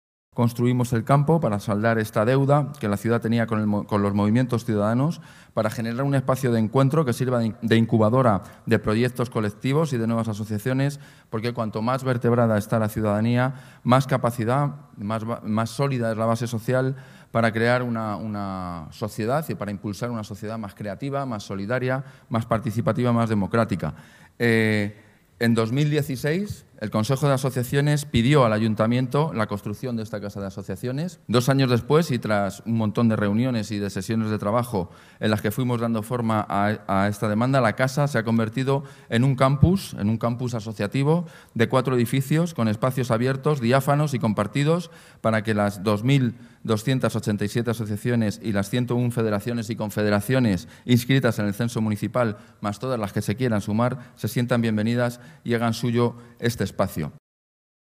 En la mañana de hoy lunes, 3 de diciembre, la alcaldesa de Madrid, Manuela Carmena, junto con el delegado de Coordinación Territorial y Cooperación Público-Social, Nacho Murgui, ha presentado el futuro Campus Asociativo de la ciudad de Madrid que se construirá en la Casa de Campo.